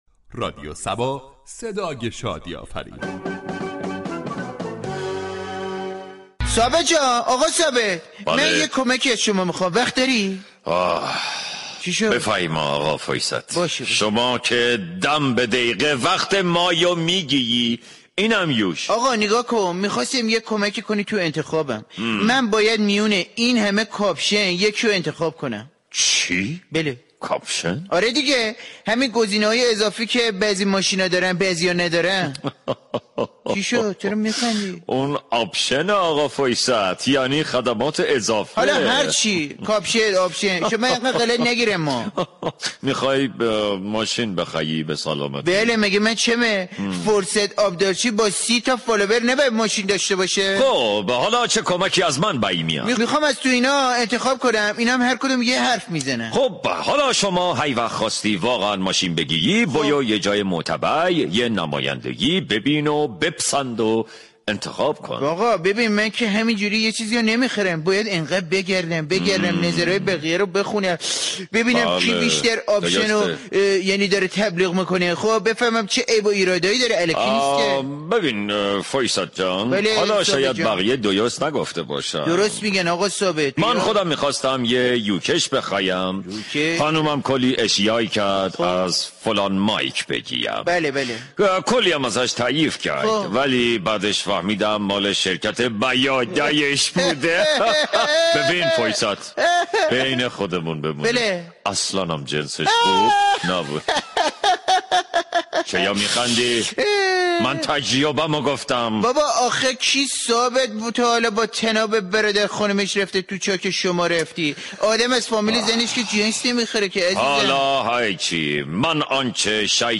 شهر فرنگ در بخش نمایشی با بیان طنز به موضوع "اهمیت قدرت انتخاب "پرداخته است ،در ادامه شنونده این بخش باشید.